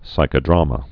(sīkə-drämə, -drămə)